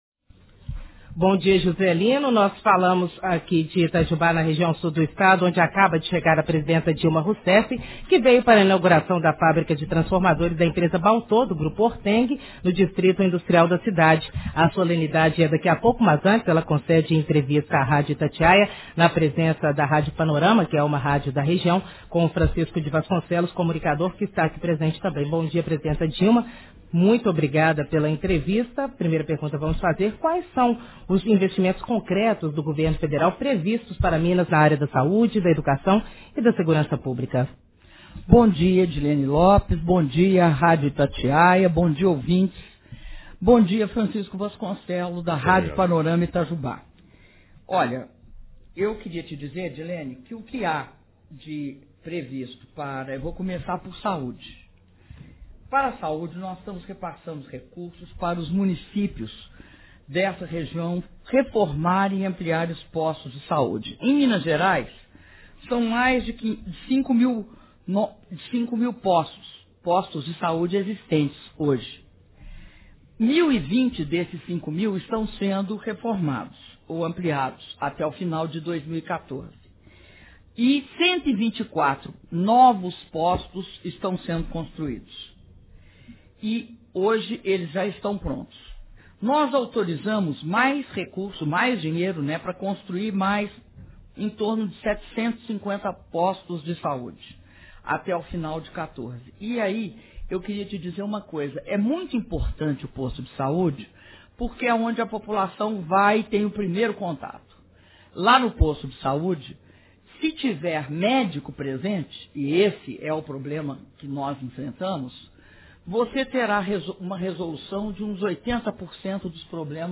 Áudio da entrevista concedida pela Presidenta da República, Dilma Rousseff, para as emissoras de rádio Panorama FM, de Itajubá e Itatiaia FM, de Belo Horizonte (Itajubá/MG)